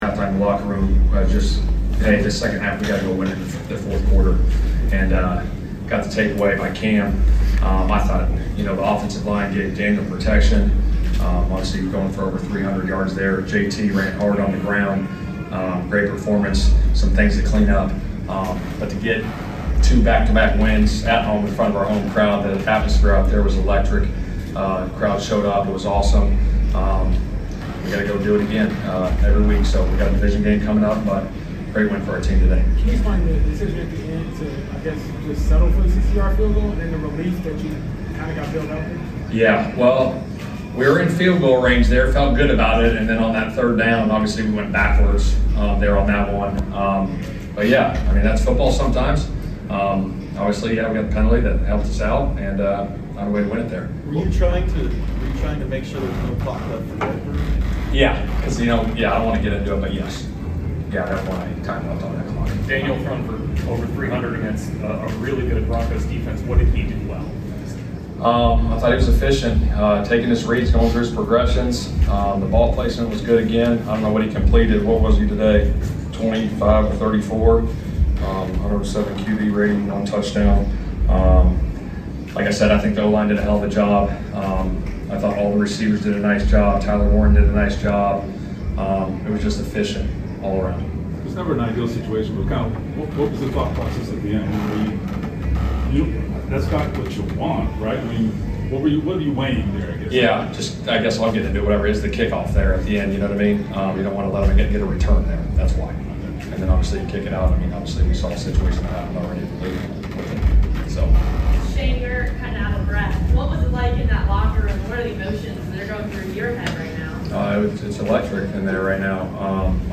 Indianapolis Colts Coach Shane Steichen Postgame Interview after defeating the Denver Broncos at Lucas Oil Stadium.